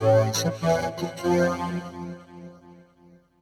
Techno / Voice / VOICEFX154_TEKNO_140_X_SC2(L).wav